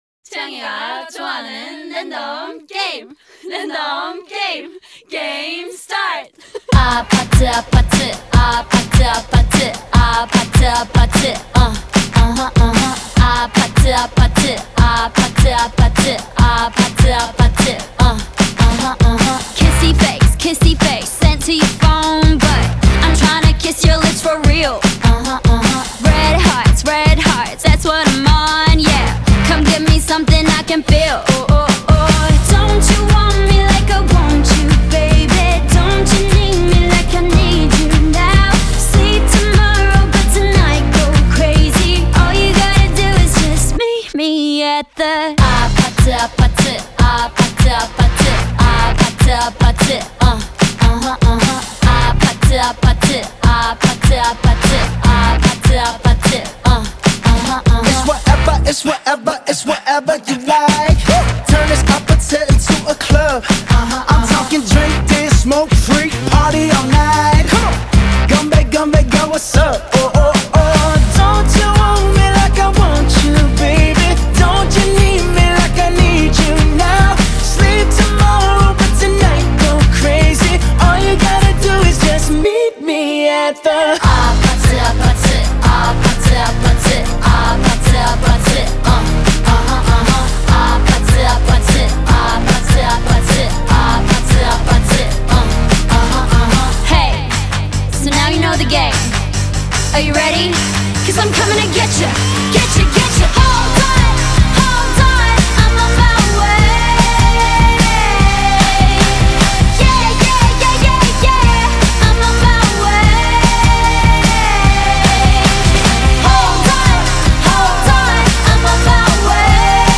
A música traz aquele som retrô de new wave e pop rock